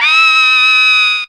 V HI WAILER.wav